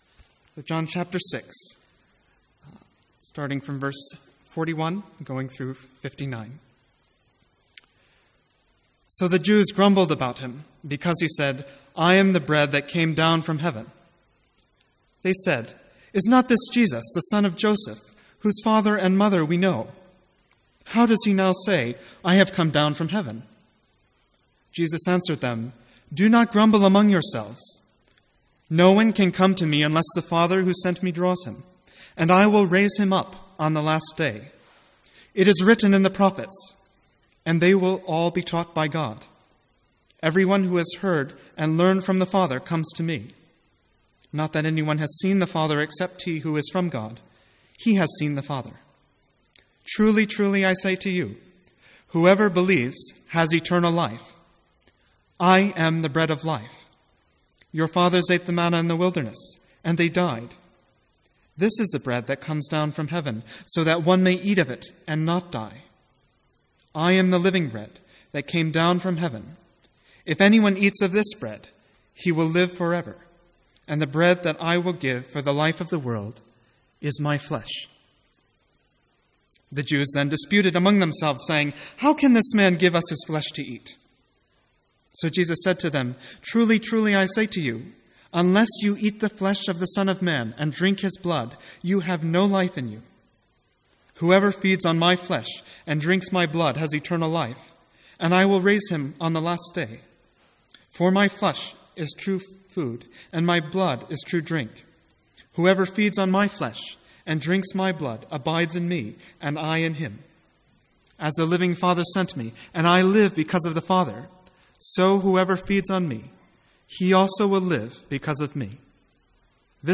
Sermons | St Andrews Free Church
From the Sunday evening series 'Hard Sayings of Jesus'.